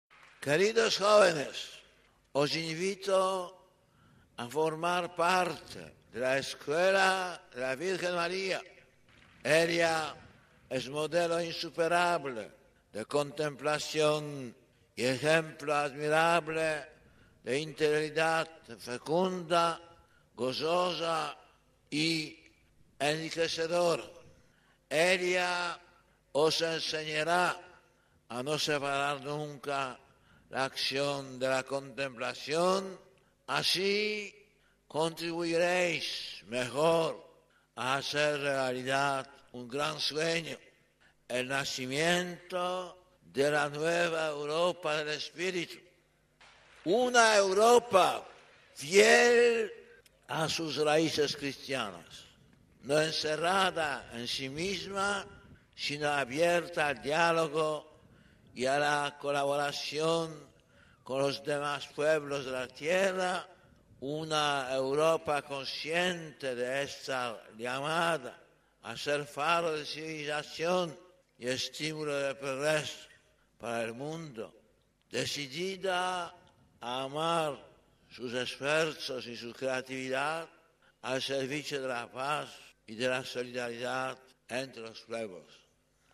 Una vez más, también ese día en Madrid, Juan Pablo II animó a la juventud a seguir a la Madre de Dios, reiterando sus incontables e infatigables exhortaciones a la fidelidad de las raíces cristianas de Europa al servicio de la paz y de la solidaridad de toda la familia humana: